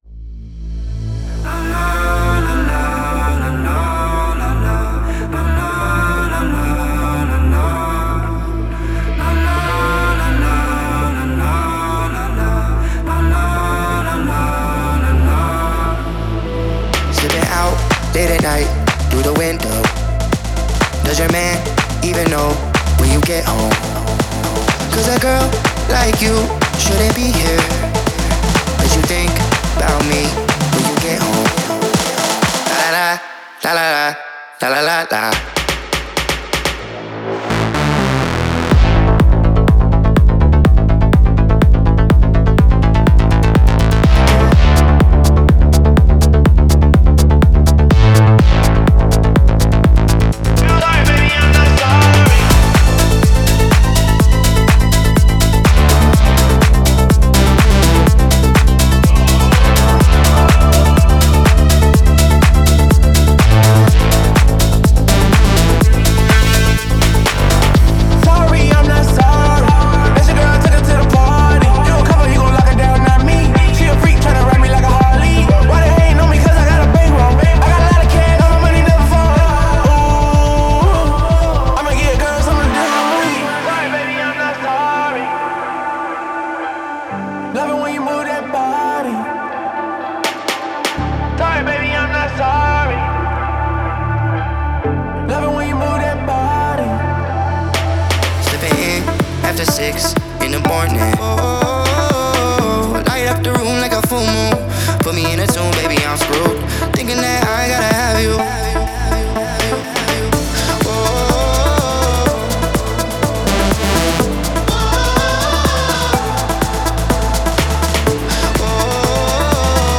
зажигательных ритмах и запоминающемся вокале
добавили яркие синтезаторы и динамичные биты